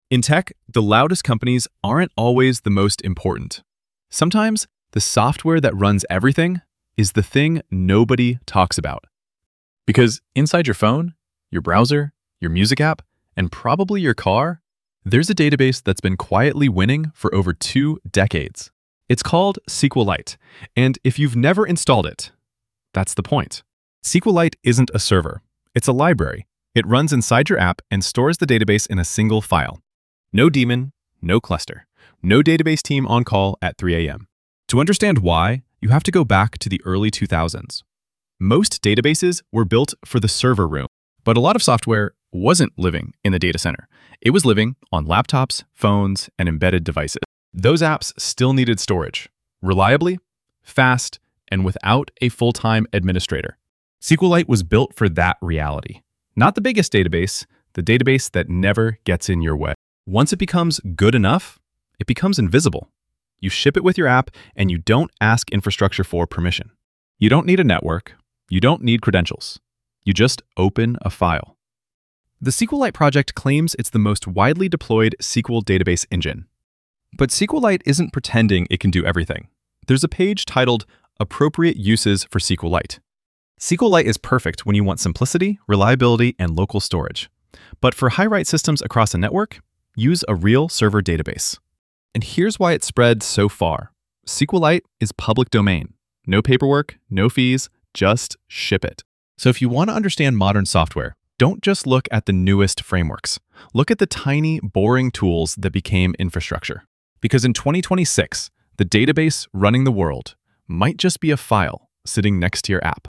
Latest published voiceover-only for the SQLite documentary-style episode.
Voiceover-only